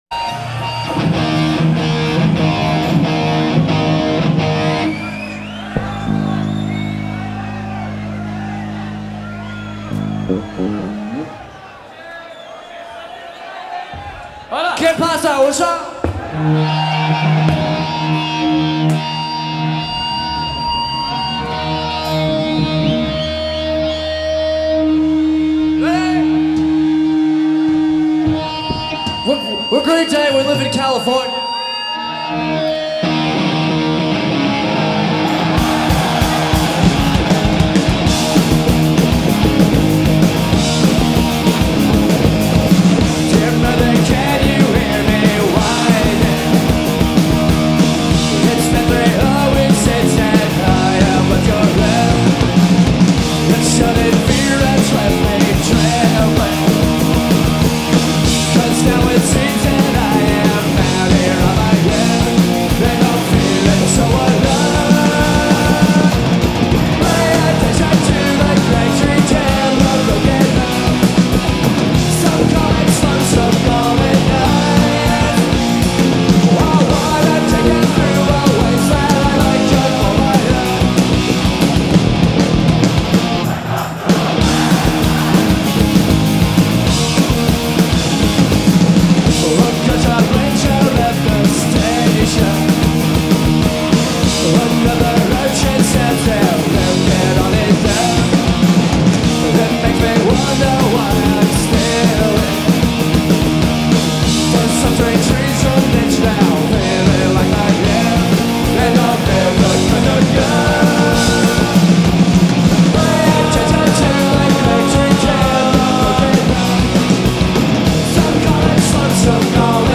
Live at Garatge Club, Barcelona 1994